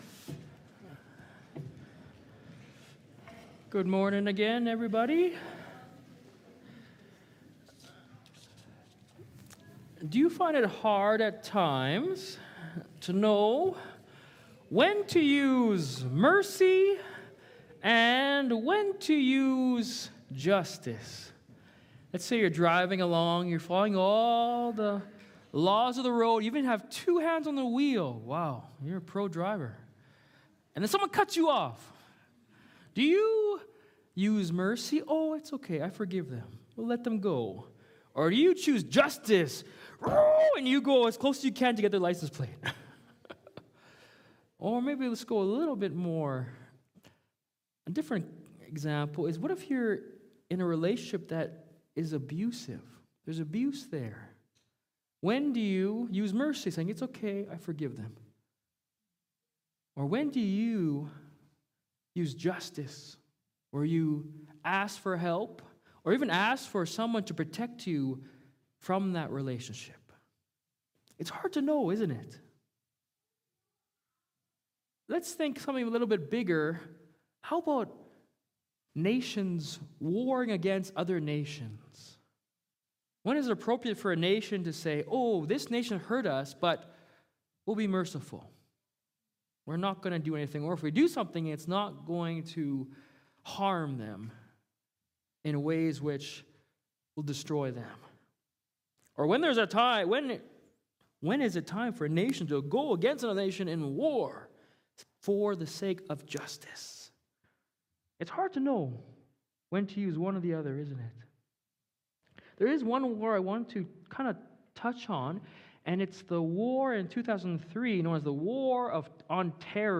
Download Download Discovering the Depths of God's Mercy Current Sermon Is God Compassionate?